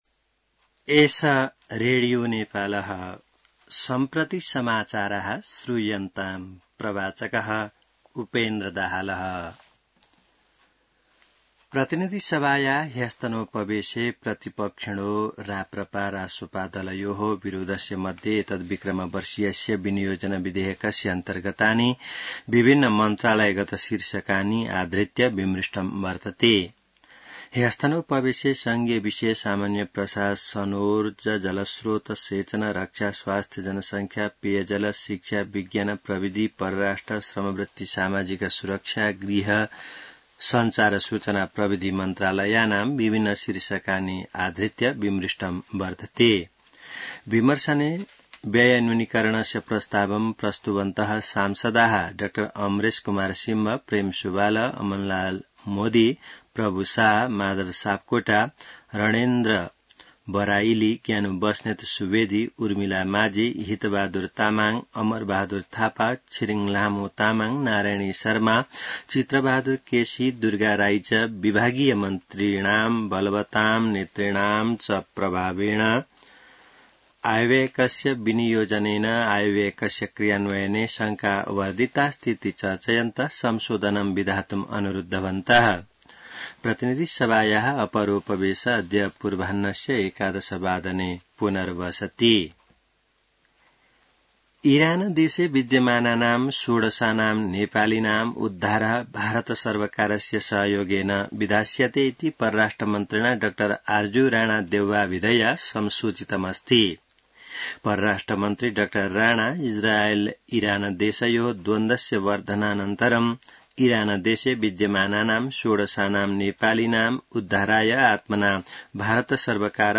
संस्कृत समाचार : ८ असार , २०८२